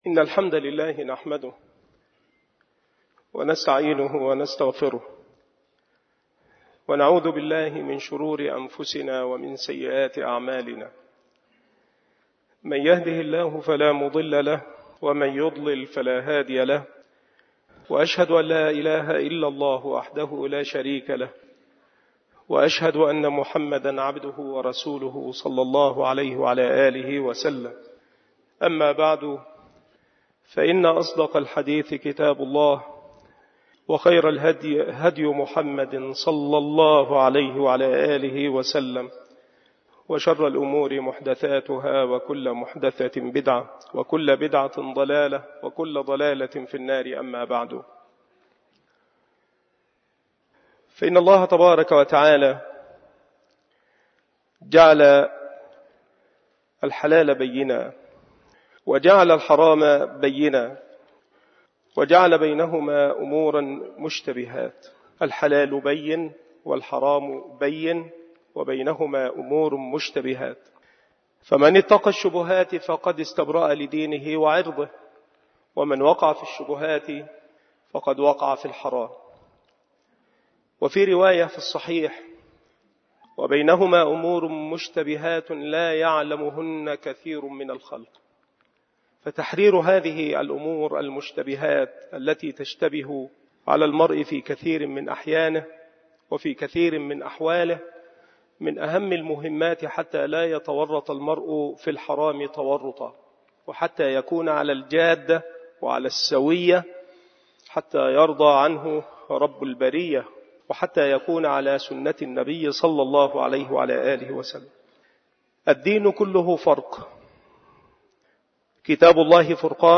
مكان إلقاء هذه المحاضرة بمسجد أولاد غانم بمدينة منوف - محافظة المنوفية - مصر